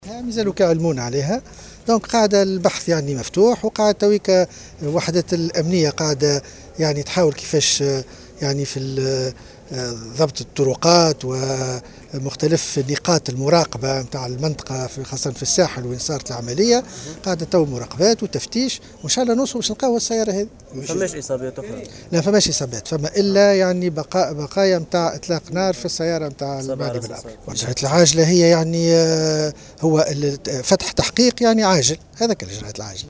قال كاتب الدولة لدى وزير الداخلية المكلف بالشؤون الأمنية، رفيق الشلي في تصريح للجوهرة أف أم اليوم الخميس، على هامش زيارة أداها للقيروان، إن البحث متواصل في قضية محاولة اغتيال النائب رضا شرف الدين، فيما تواصل الوحدات الأمنية في الساحل عموما وفي سوسة بالتحديد ضبط الطرقات ومختلف نقاط المراقبة .